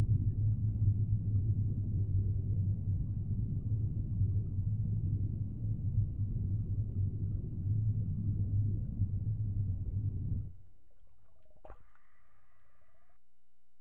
Index of /90_sSampleCDs/E-MU Producer Series Vol. 3 – Hollywood Sound Effects/Water/UnderwaterDiving
UNDERWATE00R.wav